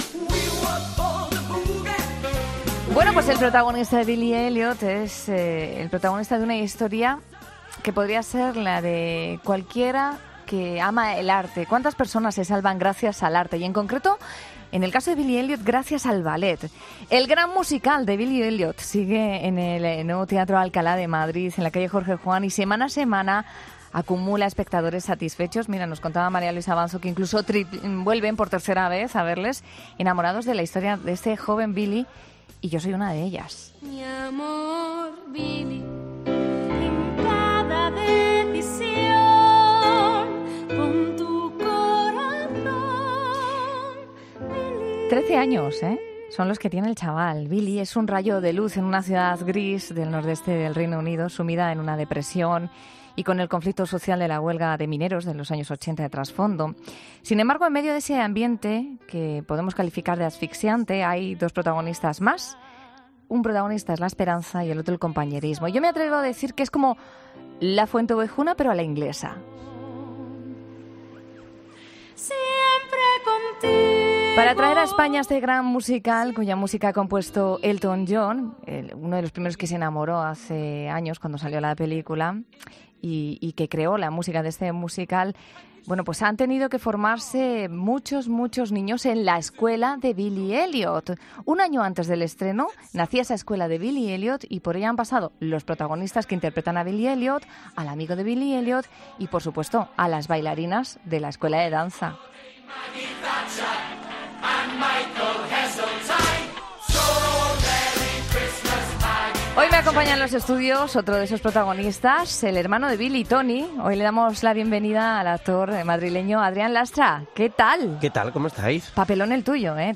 El actor madrileño nos ha acompañado en COPE Más Madrid para hablarnos de esta historia de esperanza, la de Billie, un rayo de luz en un pueblo gris del nordeste del Reino Unido.